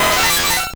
Cri d'Électhor dans Pokémon Or et Argent.